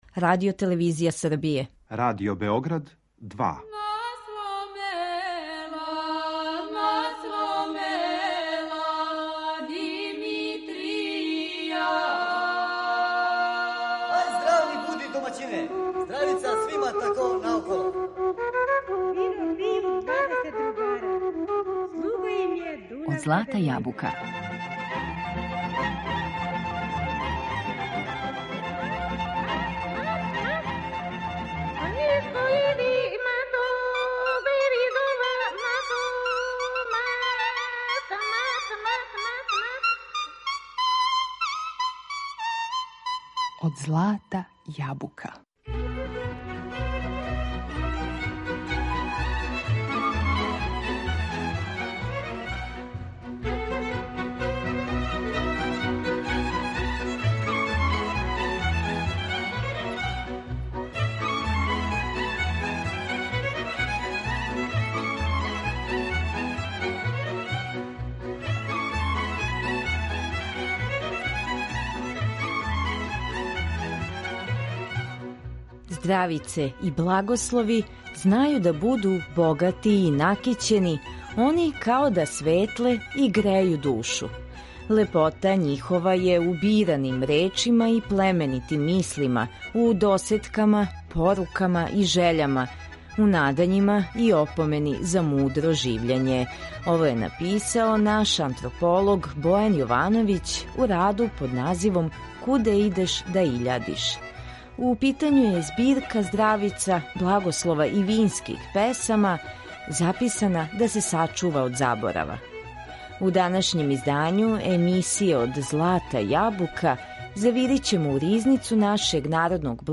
У питању је збирка здравица, благослова и винских песама, записана да се сачува од заборава. У данашњем издању емисије Од злата јабука завирићемо у ризницу нашег народног блага, пуну надахнутих умотворина, уз најлепше песме и кола из народа.